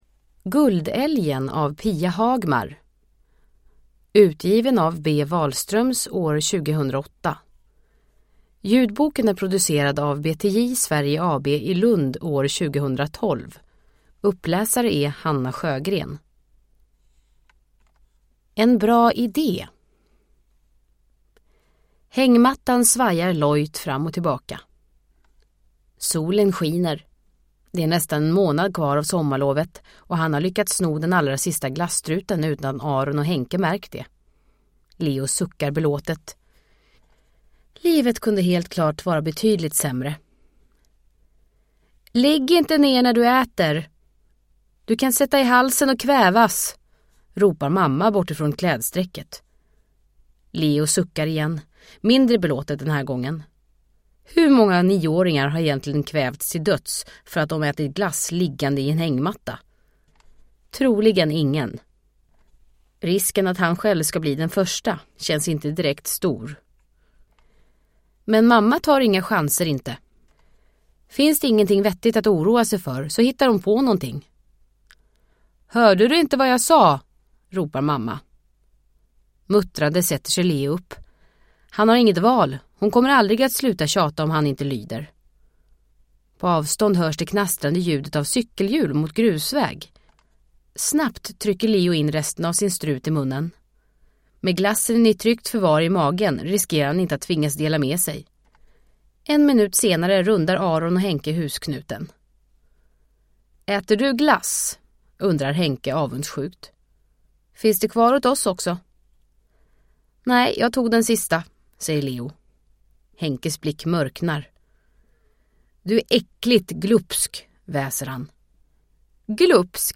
Guldälgen – Ljudbok – Laddas ner